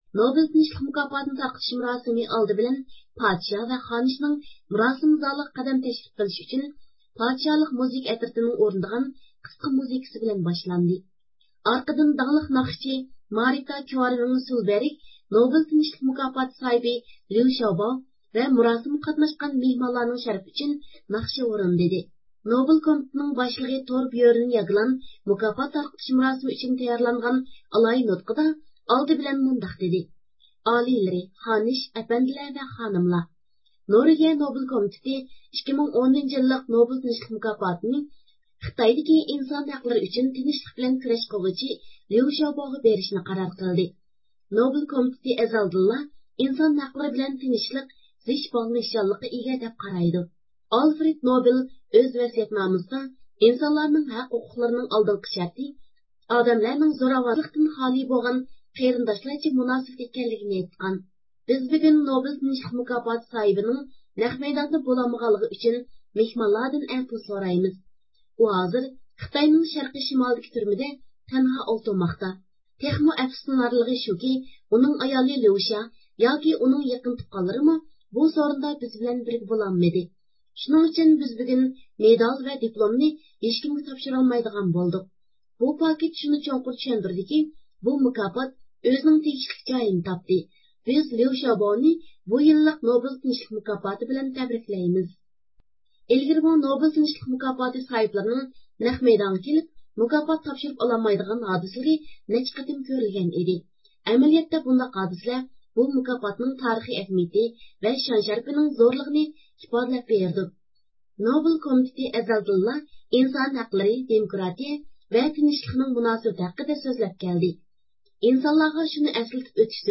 مۇراسىمغا قاتنىشىپ چىققان دۇنيا ئۇيغۇر قۇرۇلتىيىنىڭ رەئىسى رابىيە قادىر خانىم زىيارىتىمىزنى قوبۇل قىلىپ ئۆزىنىڭ تەسىراتلىرى ھەققىدە توختالدى.